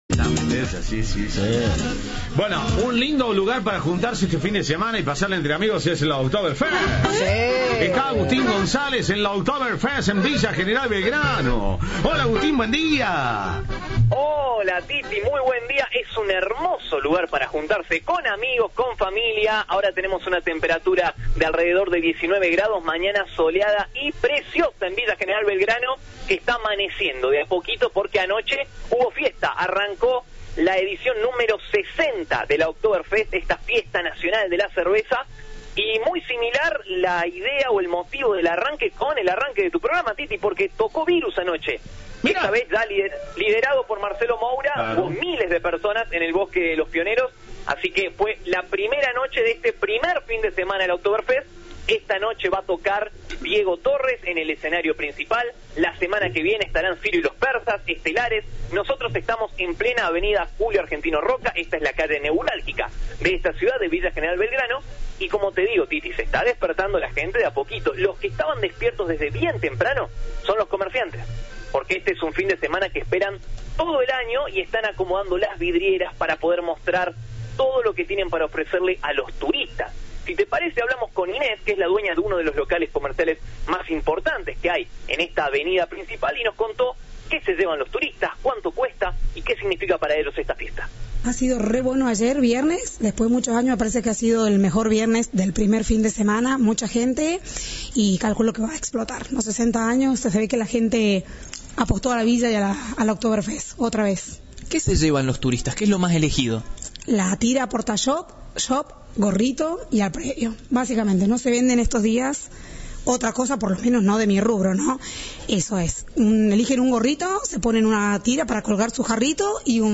Informe de